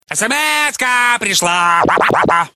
SMS_ka_prishla_.mp3